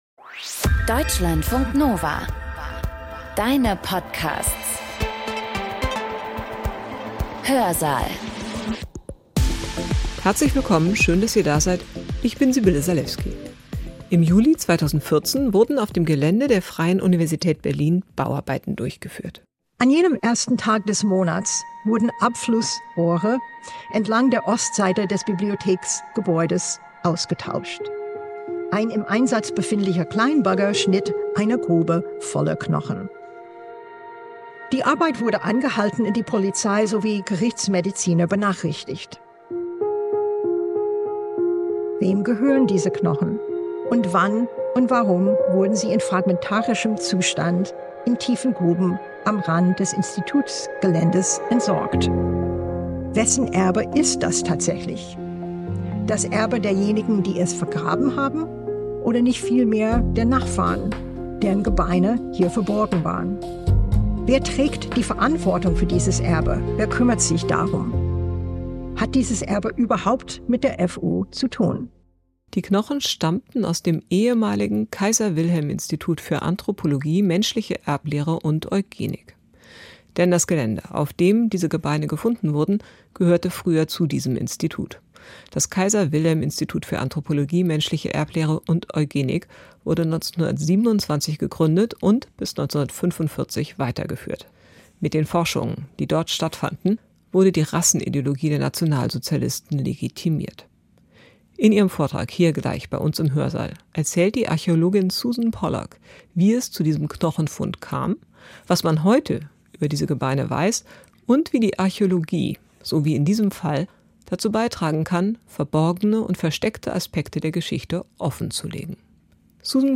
ein Vortrag